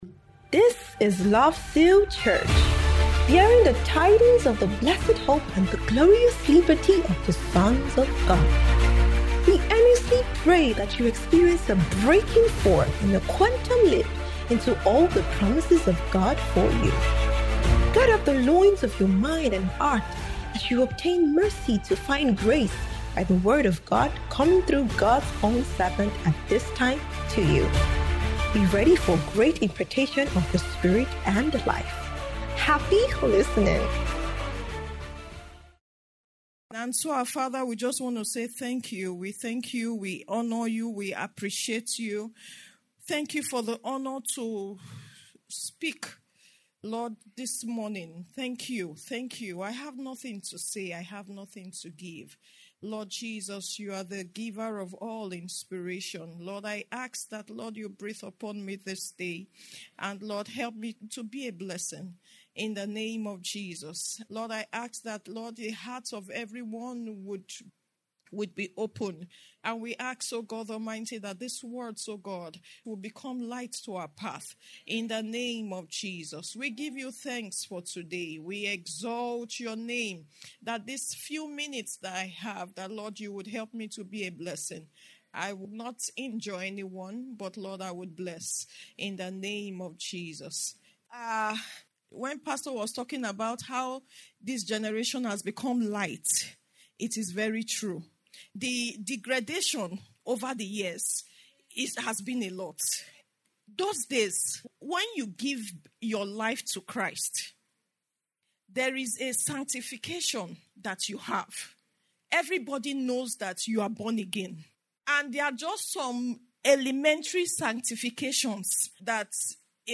Marriageable Singles’ Ingathering